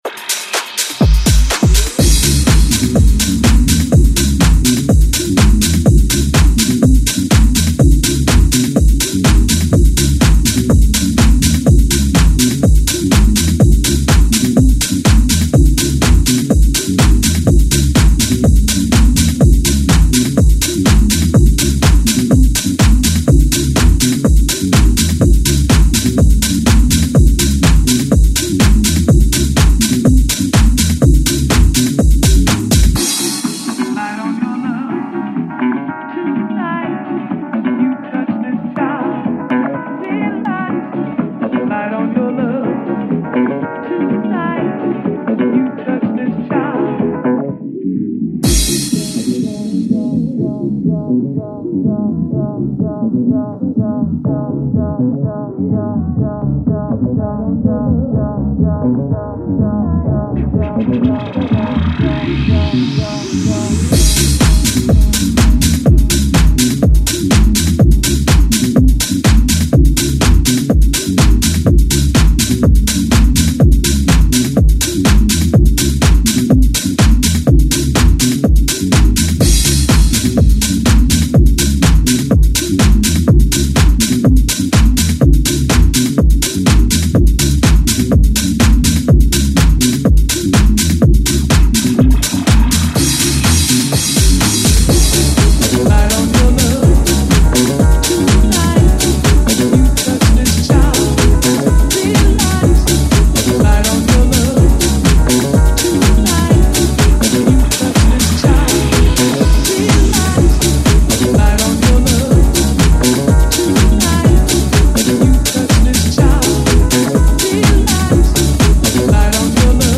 filtered disco flavour